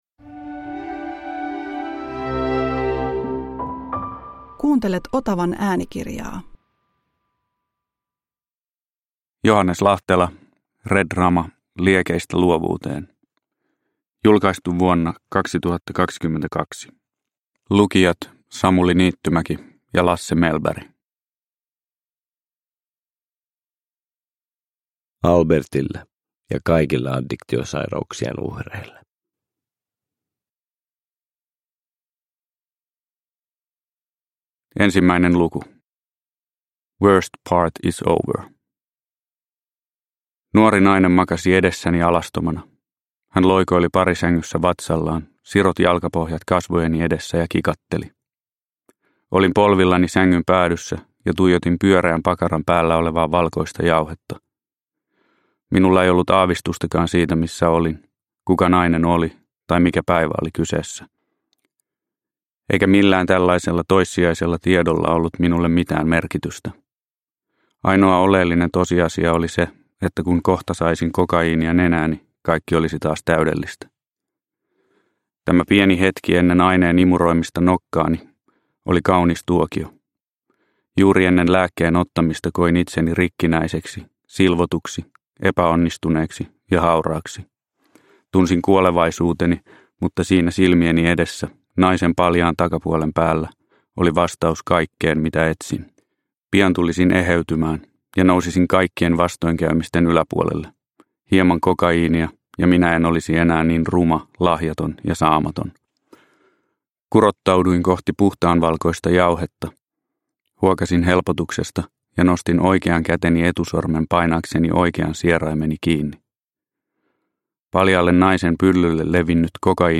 Redrama – Ljudbok – Laddas ner